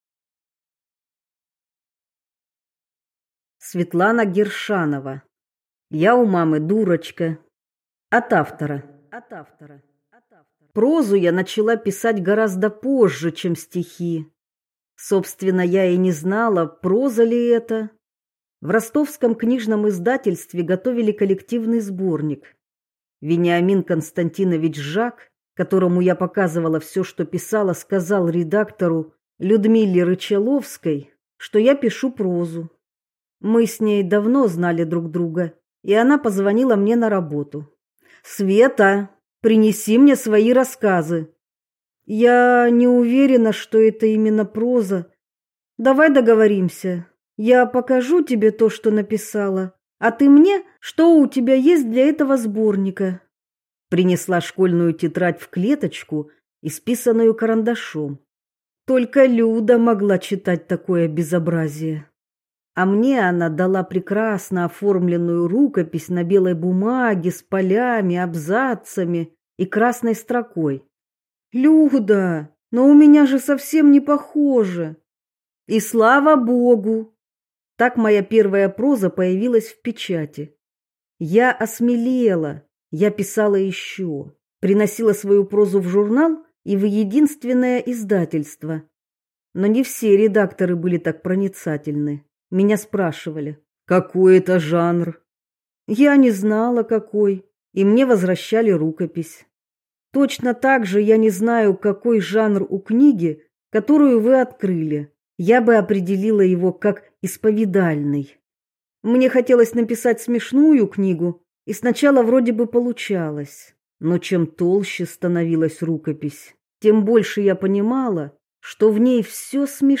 Аудиокнига Я у мамы дурочка | Библиотека аудиокниг